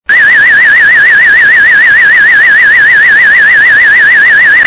Sound Effects Alarm Siren